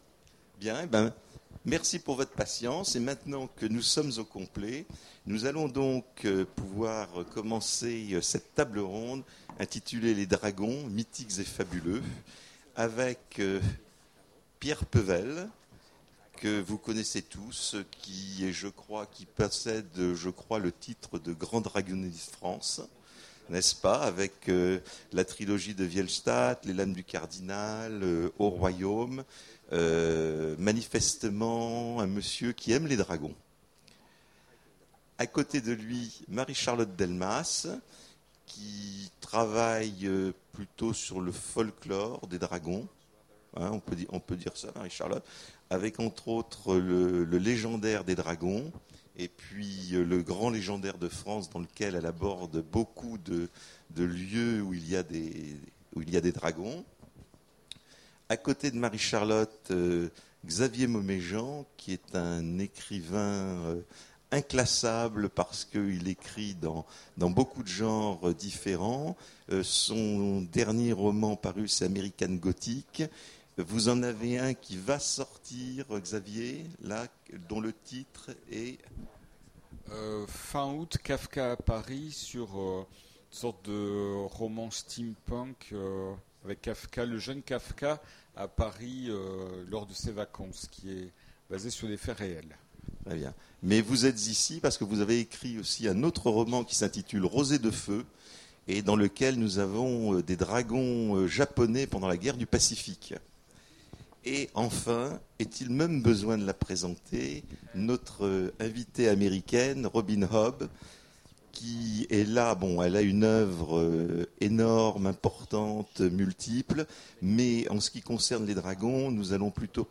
Imaginales 2015 : Conférence Les dragons...